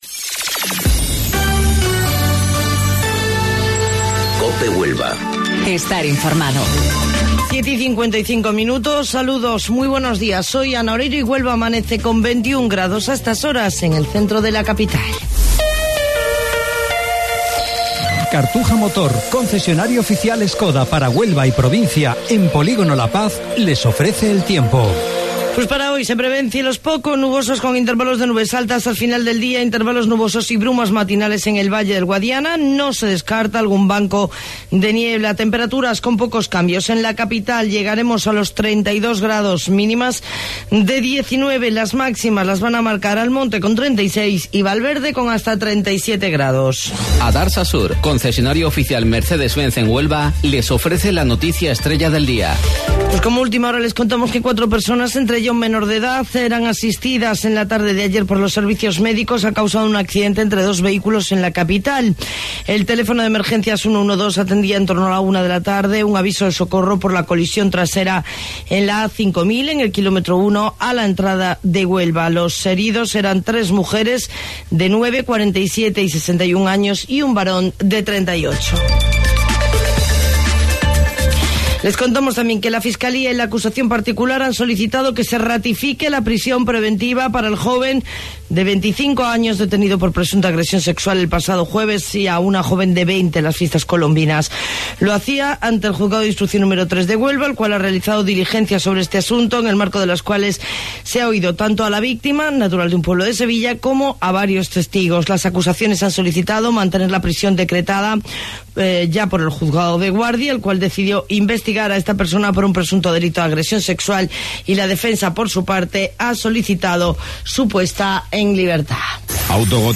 AUDIO: Informativo Local 07:55 del 6 de Agosto